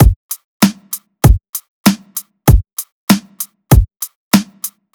FK097BEAT1-L.wav